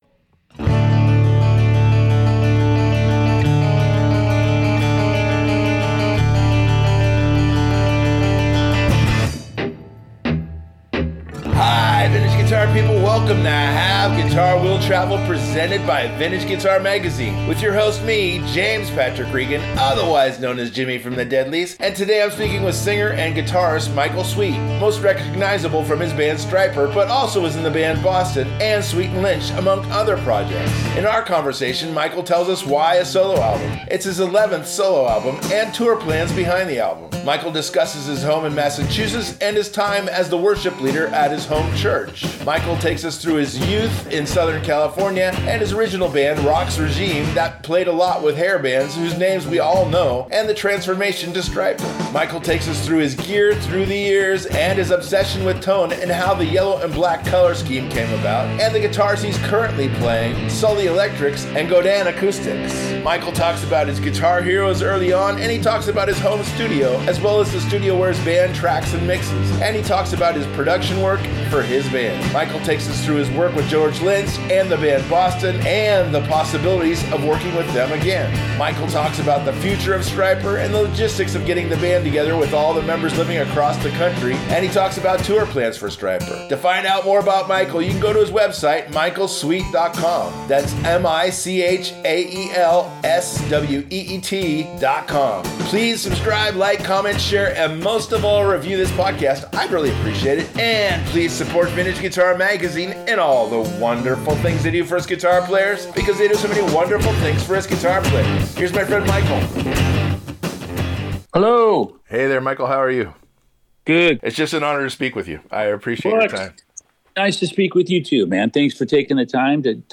In their conversation Michael tells us why a solo album… it’s his 11th solo album and tour plans behind the album.